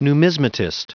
Prononciation du mot numismatist en anglais (fichier audio)
Prononciation du mot : numismatist
numismatist.wav